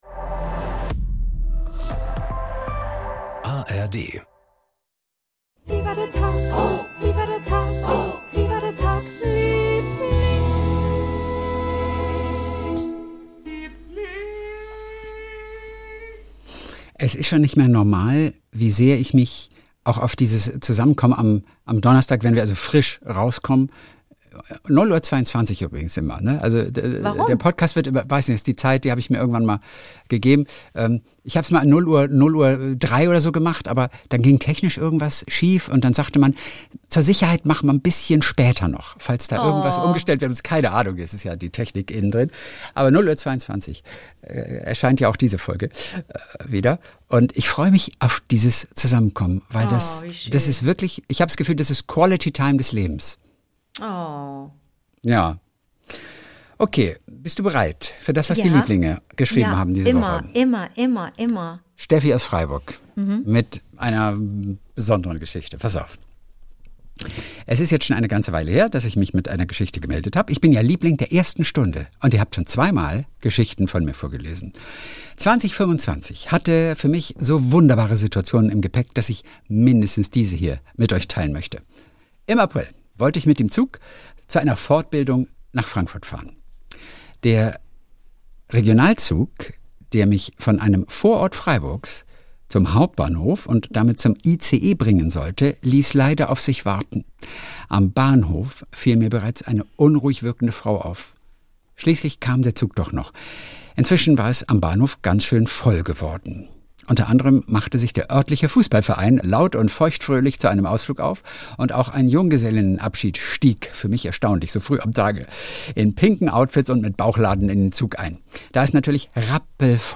Jeden Montag und Donnerstag Kult: SWR3-Moderator Kristian Thees und seine beste Freundin Anke Engelke erzählen sich gegenseitig ihre kleinen Geschichtchen des Tages.